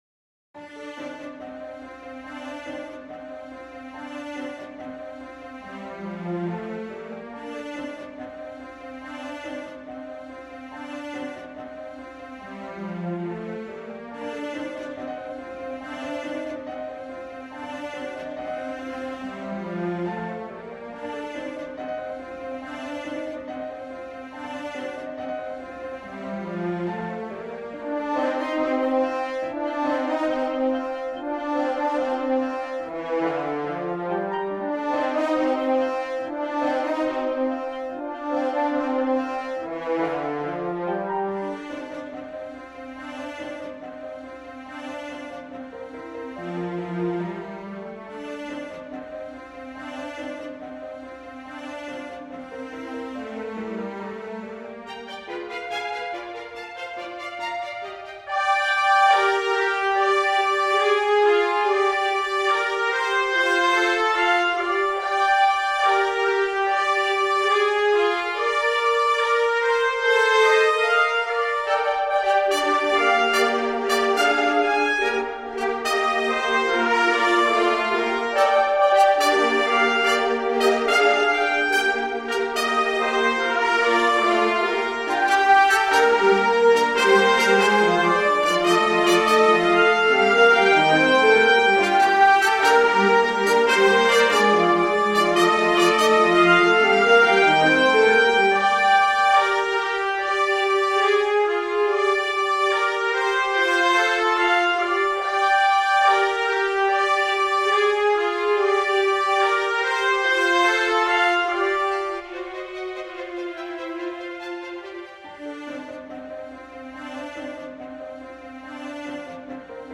Subsequently, the project was processed through an AI model on the AIVA platform, and here is the effect.